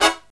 HIGH HIT02-L.wav